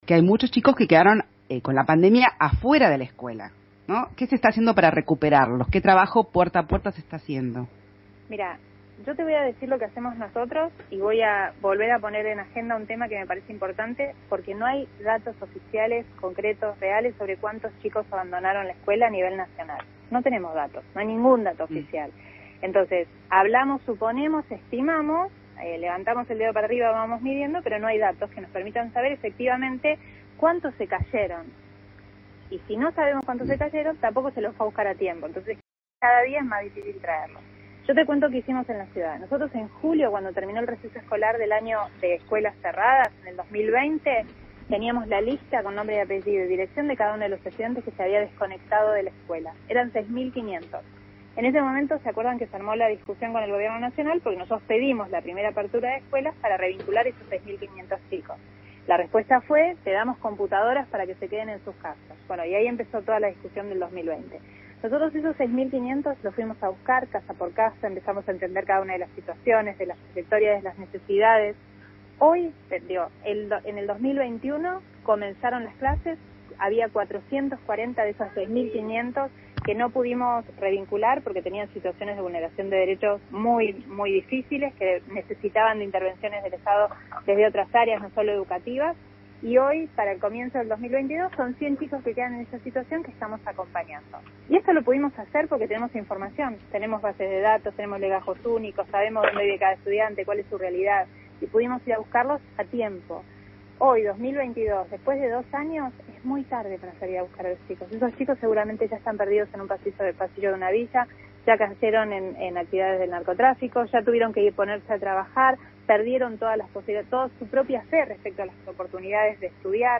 Se trata de Soledad Acuña, quien en una entrevista con radio Rivadavia empezó diciendo que la deserción escolar en CABA, desde marzo de 2020, subió a 6.500 alumnos.